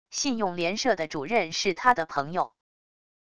信用联社的主任是他的朋友wav音频生成系统WAV Audio Player